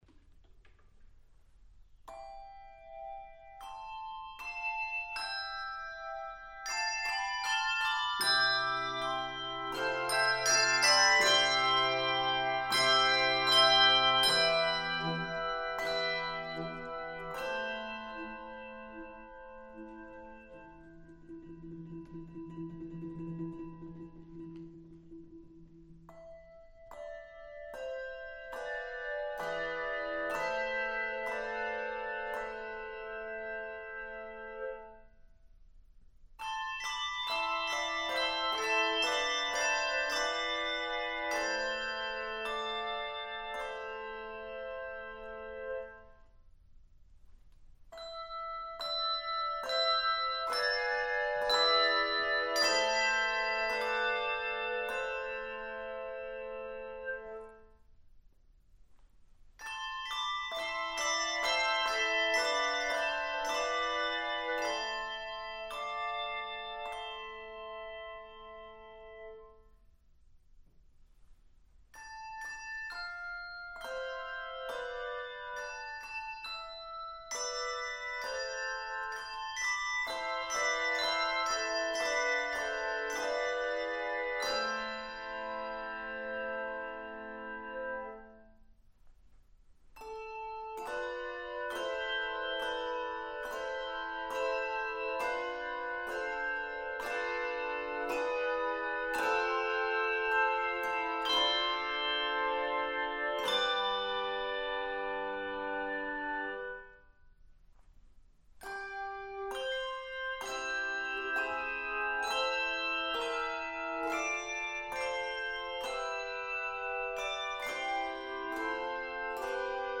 Key of a minor.